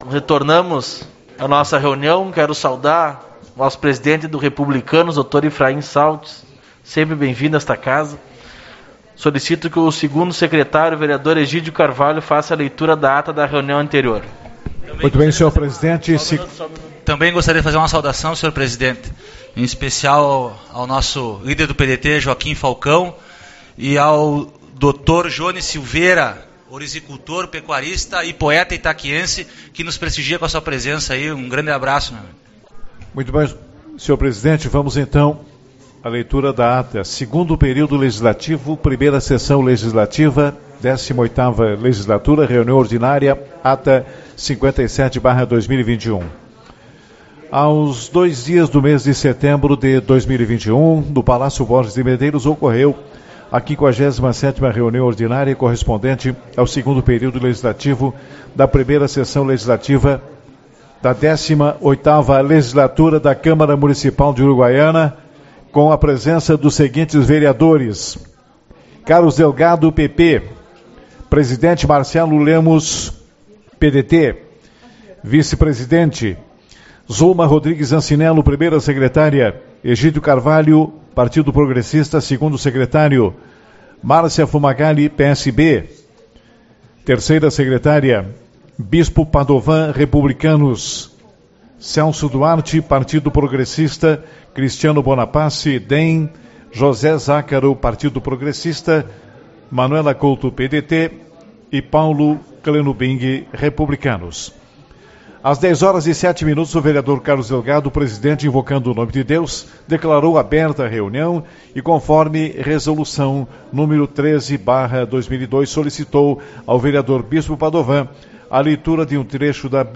09/09 - Reunião Ordinária